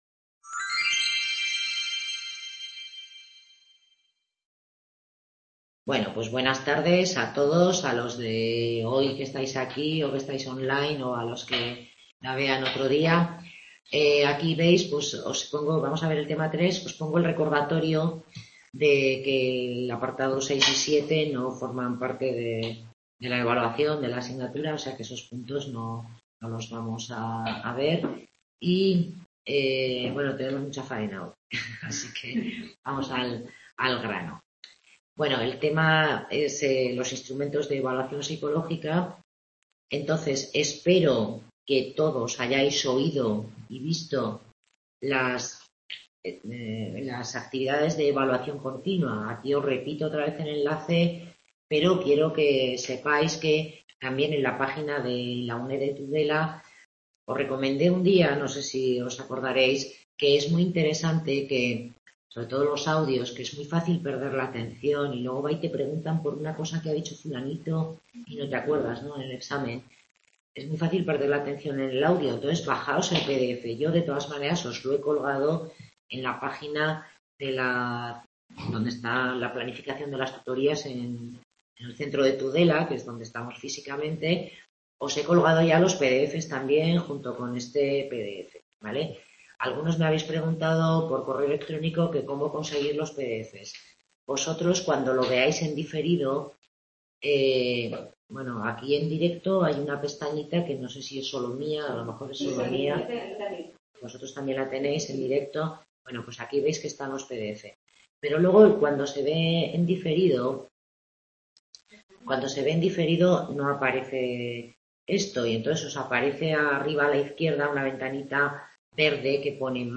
Tutoría grupal sobre el tema 3 de Evaluación Psicológica: Instrumentos de evaluación Se ven algunos test y páginas de distribución de material psicotécnico con información sobre los distintos tests